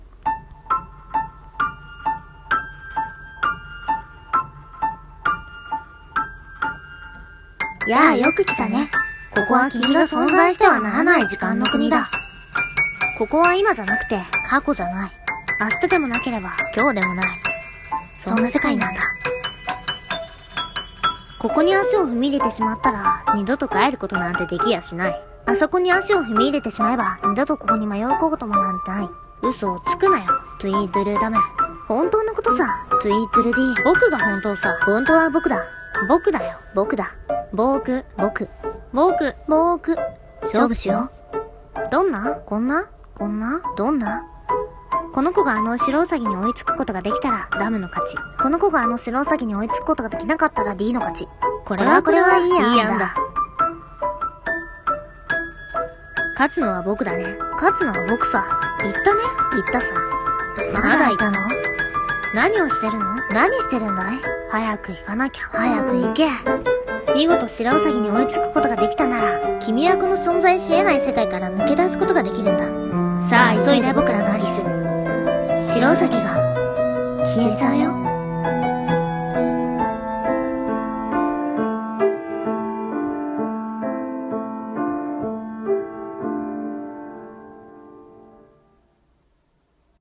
【二人声劇】時間の国のアリス